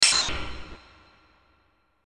play_sound_effect.mp3